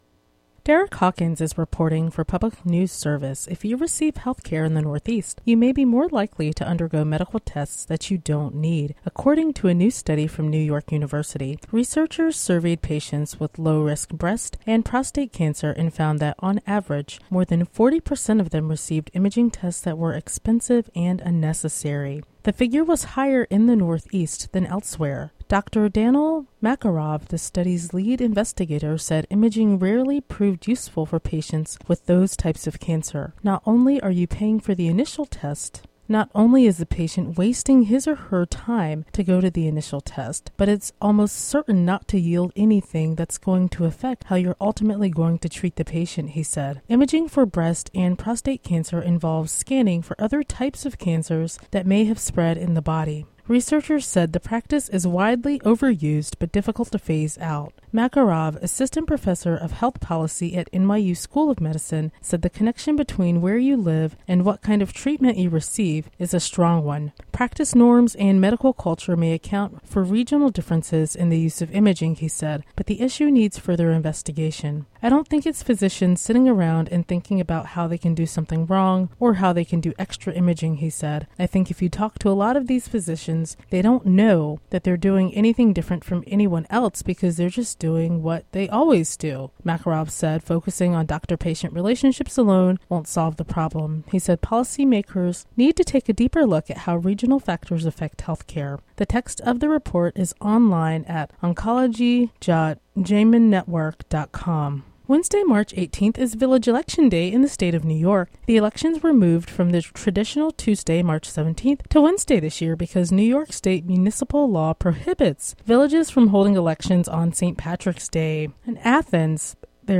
Local news and weather for Wednesday, March 18, 2015.